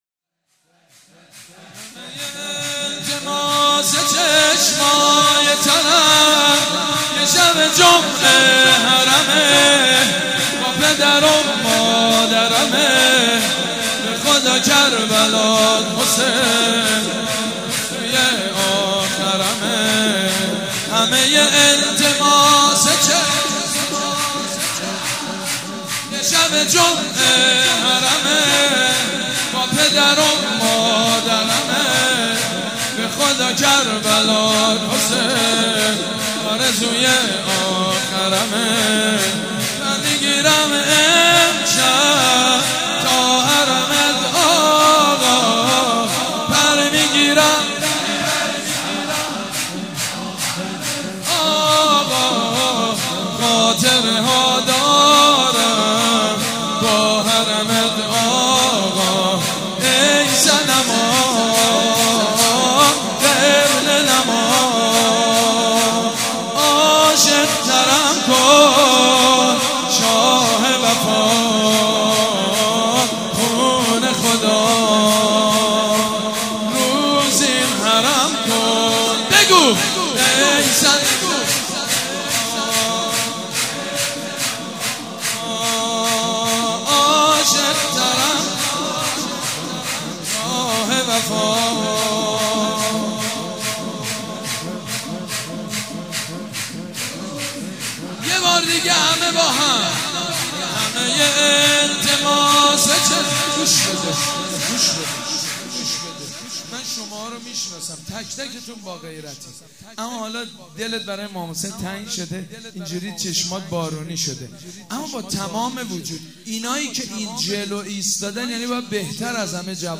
سرود: همه ی التماس چشمای ترم